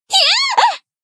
BA_V_Aru_Newyear_Battle_Damage_1.ogg